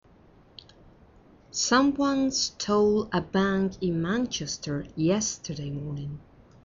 Escucha a la Redactora Jefe y completa las noticias con las siguientes palabras: